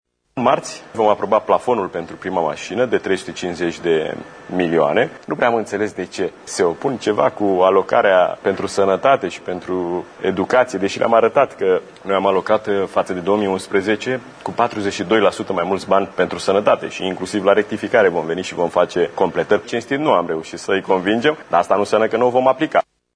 Invitat la Realitatea TV, Ministrul Finanţelor, Darius Vâlcov a dat de înţeles că există probleme asupra cărora cele două părţi nu au ajuns la un acord, printre care şi calendarul liberalizării preţurilor la gaze până în 2021, a cărui modificare este cerută de specialiştii FMI şi ai Comisiei Europene.